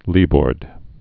(lēbôrd)